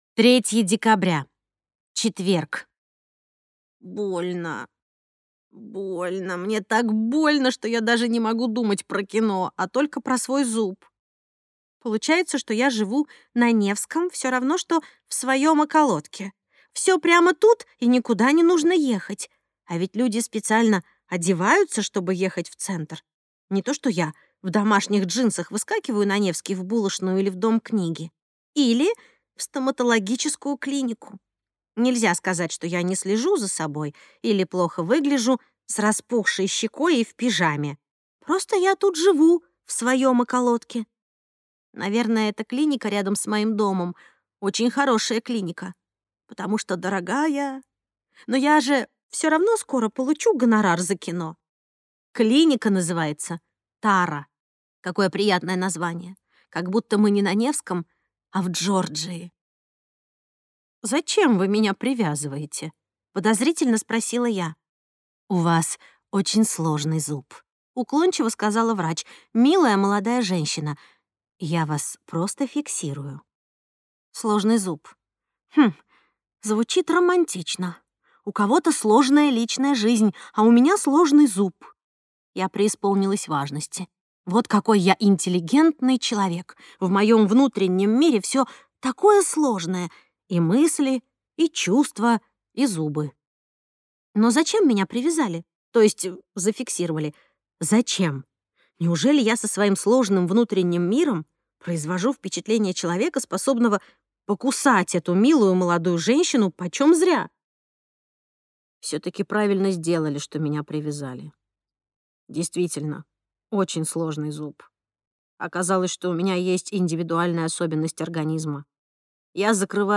Аудиокнига Взрослые игры | Библиотека аудиокниг